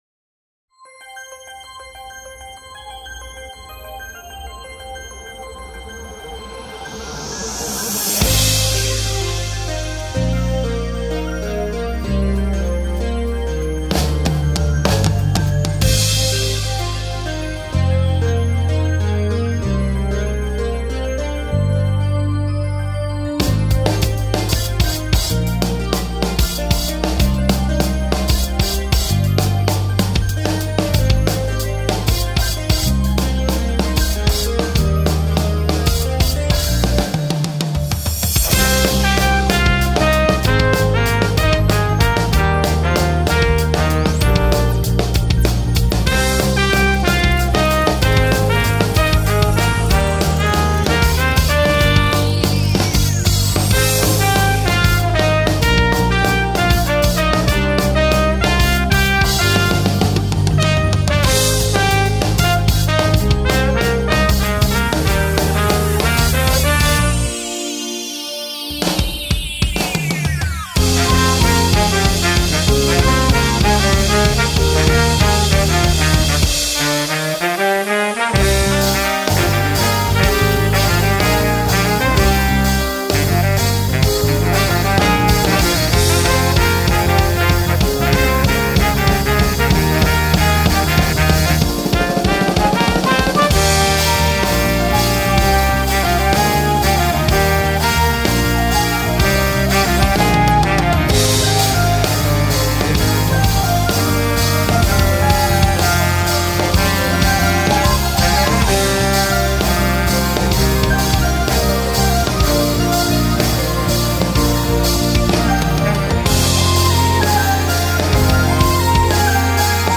Featuring the Laser Harp, a full on brass section